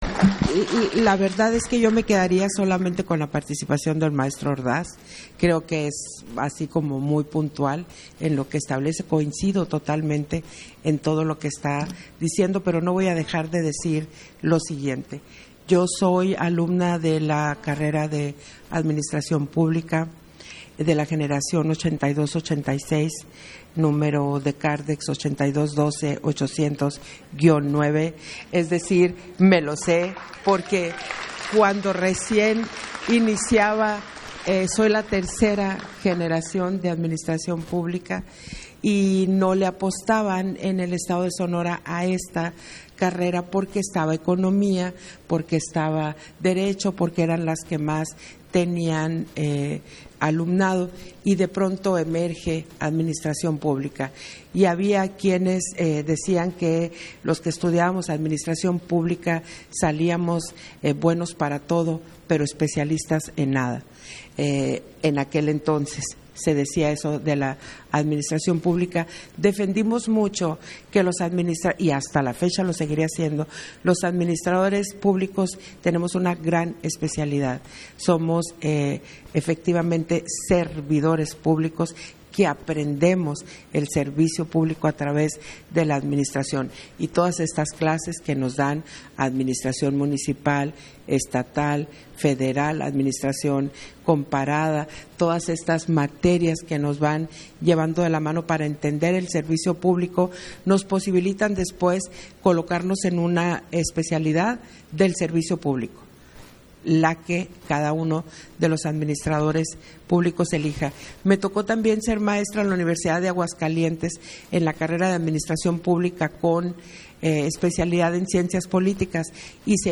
Intervenciones de Guadalupe Taddei, en el Foro Juventud y Democracia